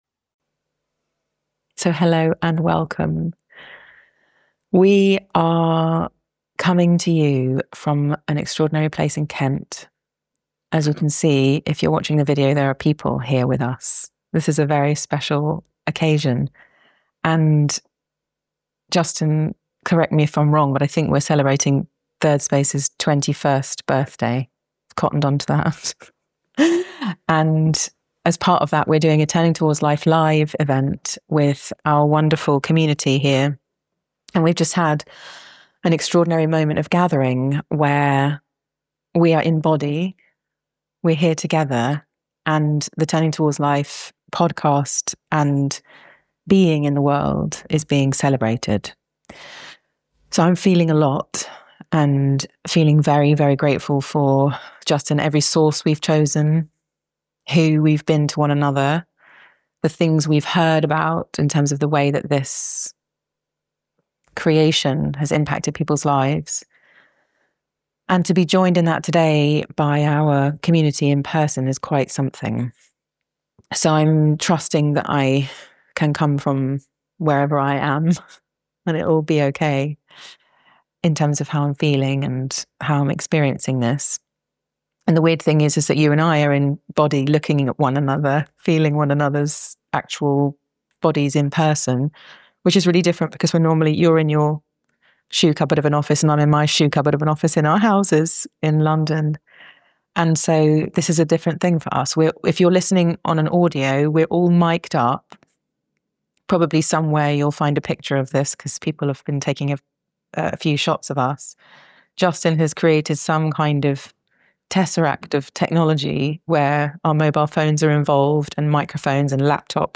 We recorded this conversation in front of a live audience of Thirdspace alumni, at our March 2026 Retreat.